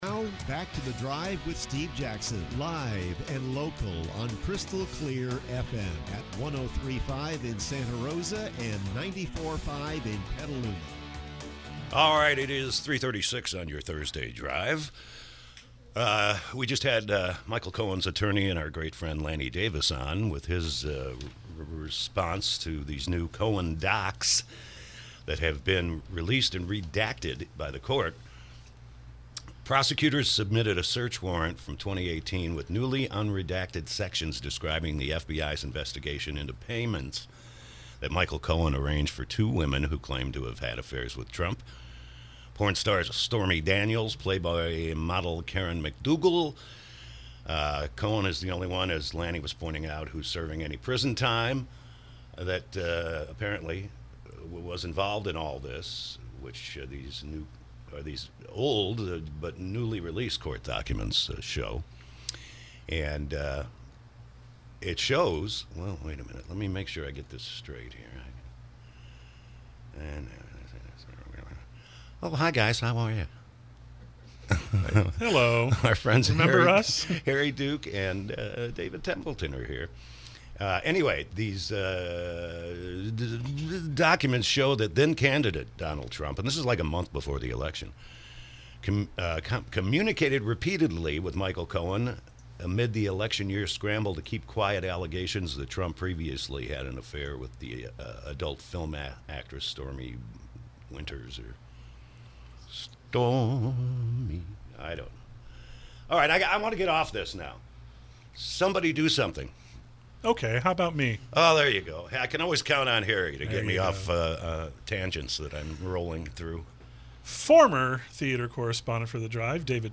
KSRO Interview: “Wretch Like Me”